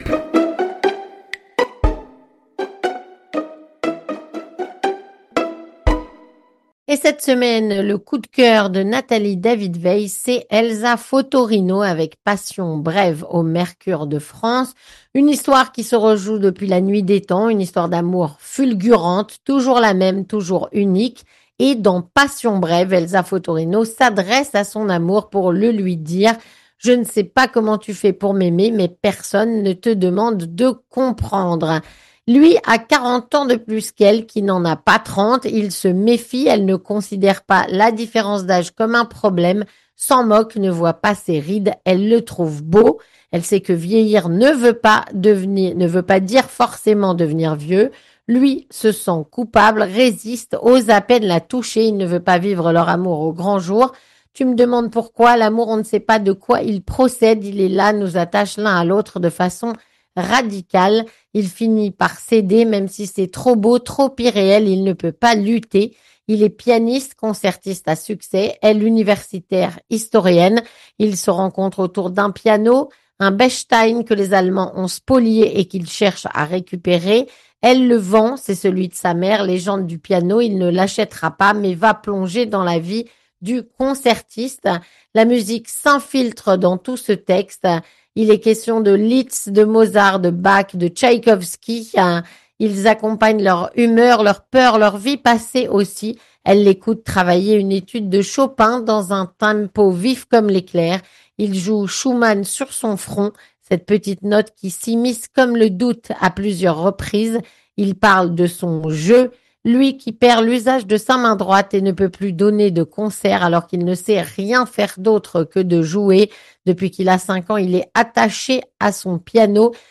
une chronique écrite par